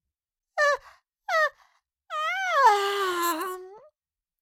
Cartoon Little Child, Voice, Yawn 4 Sound Effect Download | Gfx Sounds
Cartoon-little-child-voice-yawn-4.mp3